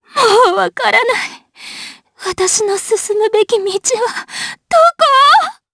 DarkFrey-vox-dia_03_jp.wav